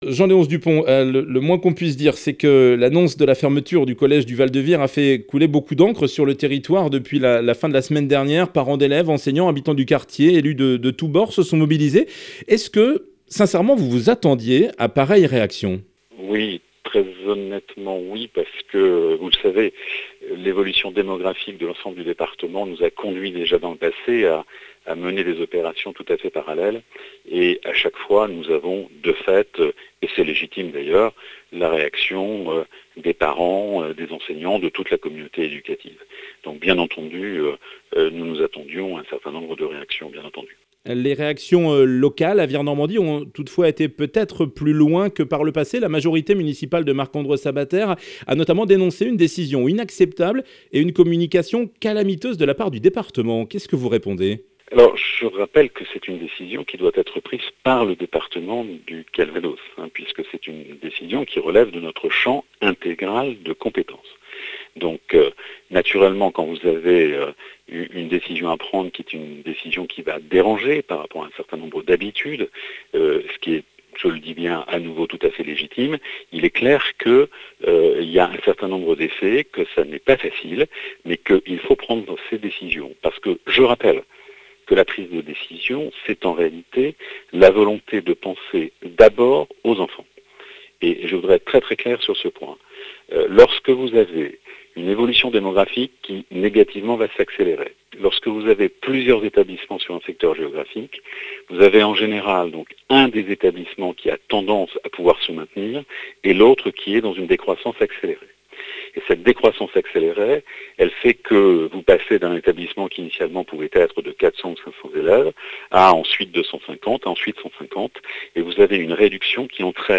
Interview de Jean-Léonce Dupont